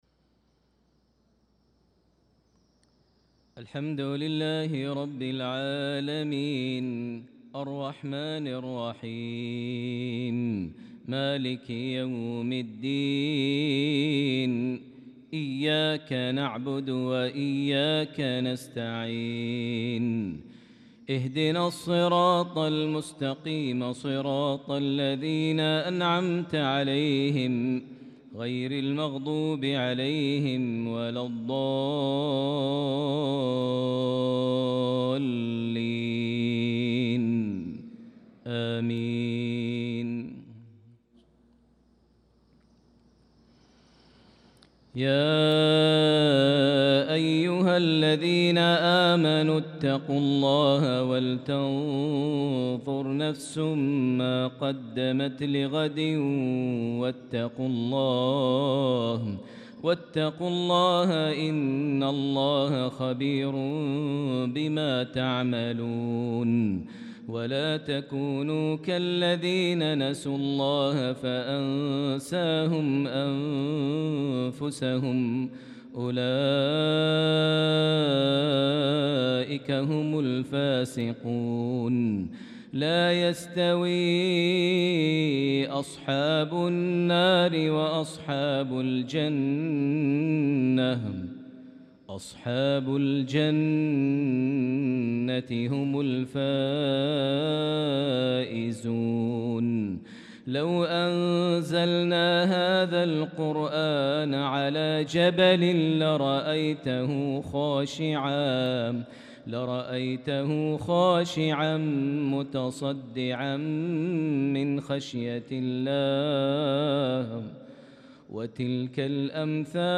صلاة المغرب للقارئ ماهر المعيقلي 22 شوال 1445 هـ
تِلَاوَات الْحَرَمَيْن .